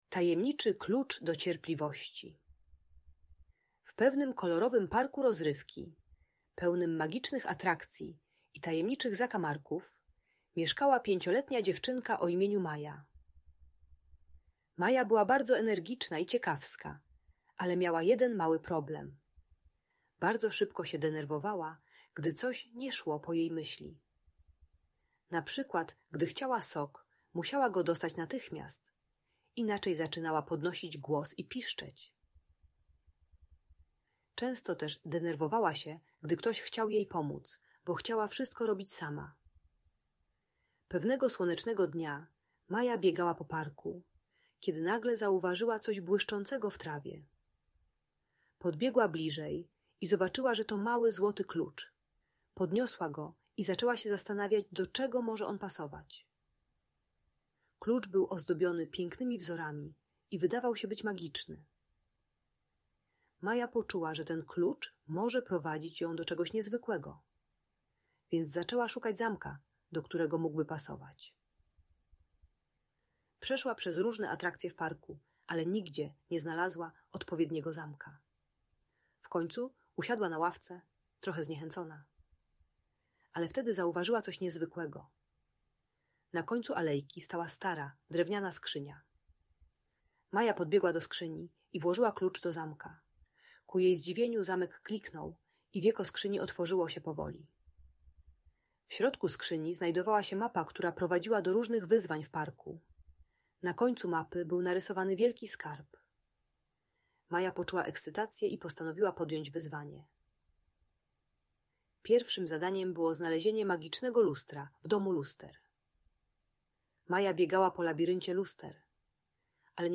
Tajemniczy klucz do cierpliwości - Audiobajka